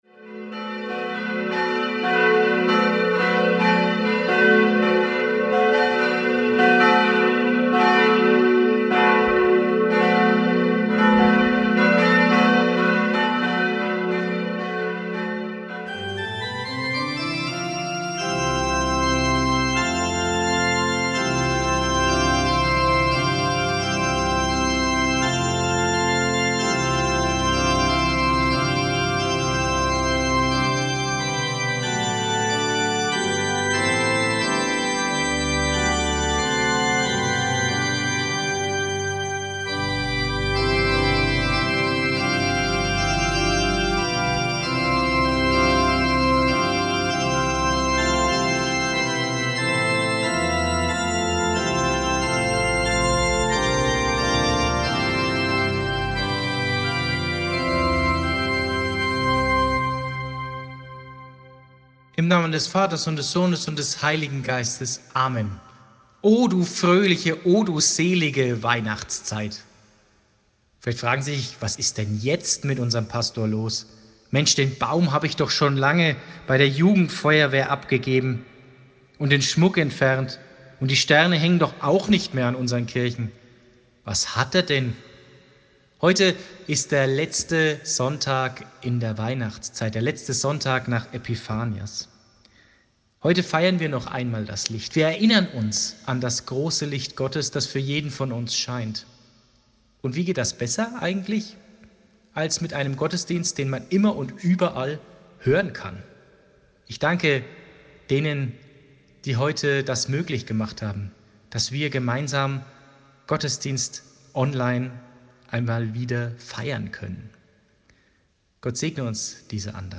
Whatsapp Audioandachten zum letzten Sonntag nach Epiphanias